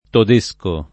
todesco [ tod %S ko ]